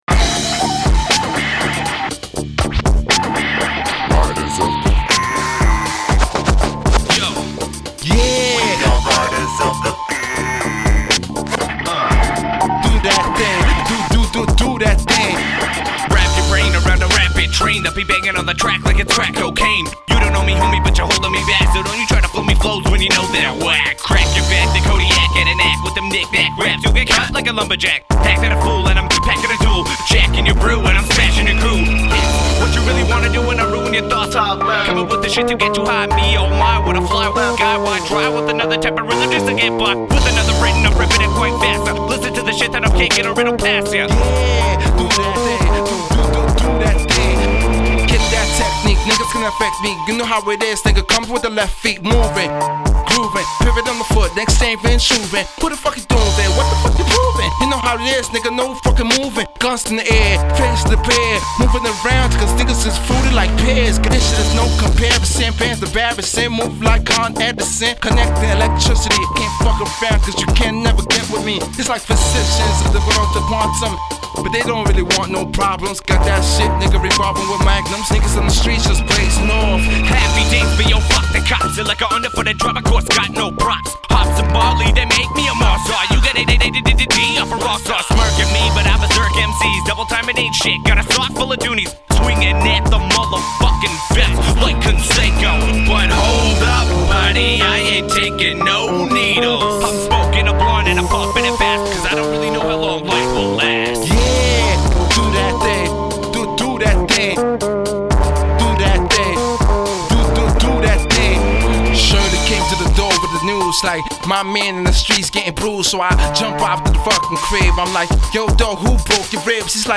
Recorded at Ground Zero Studios and Seattle Chop Shop